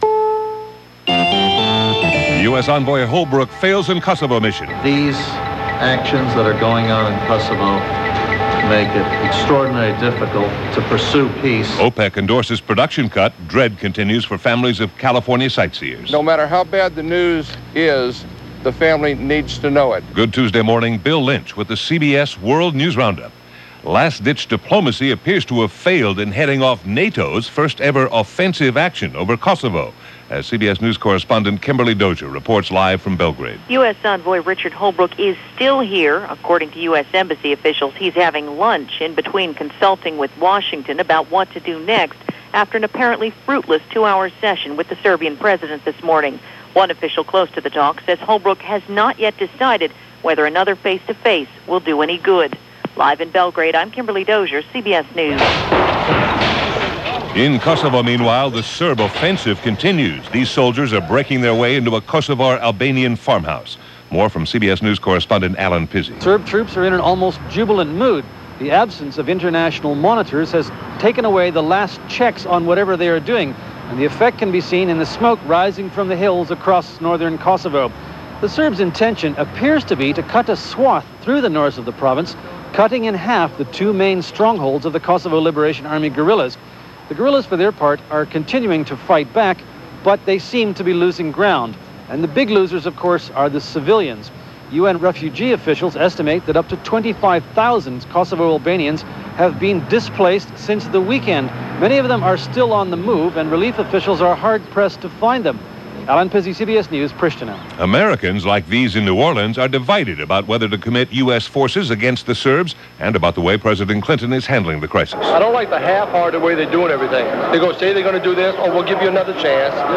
And that’s just a small sample of what happened this March 23rd in 1999 as reported by The CBS World News Roundup.